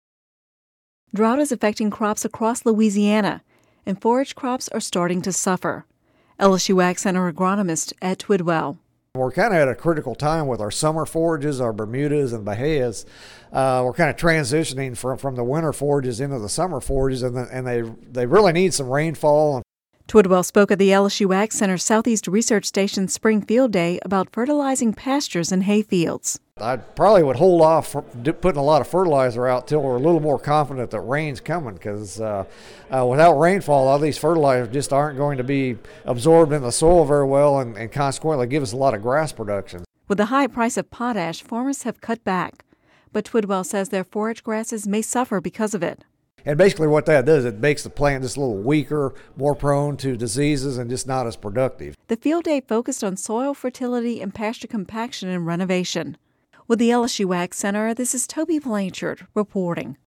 (Radio News 05/19/11) Drought is affecting crops across Louisiana, and forage crops are starting to suffer.